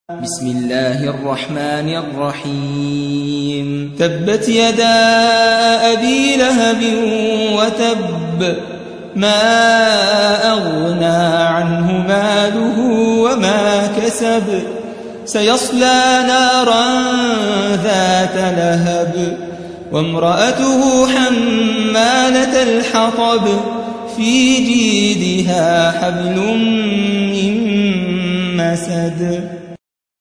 111. سورة المسد / القارئ